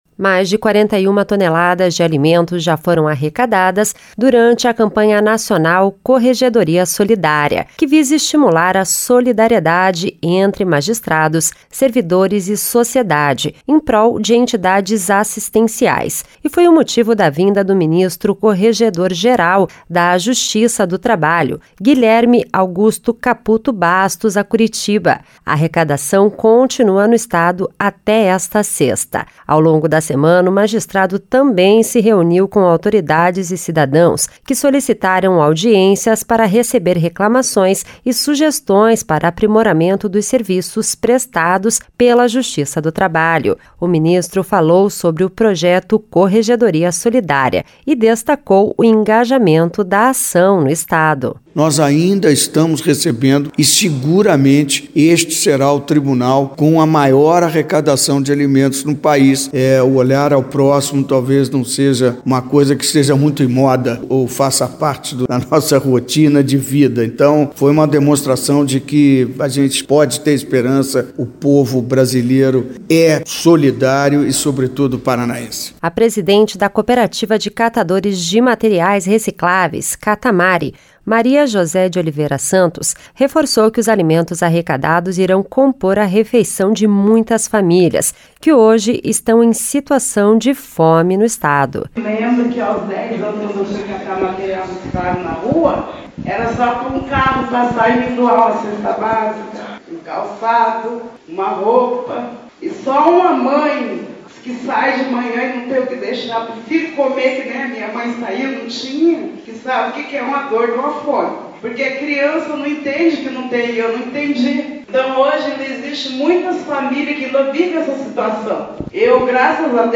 O ministro falou sobre o projeto Corregedoria Solidária e destacou o engajamento da ação no Estado.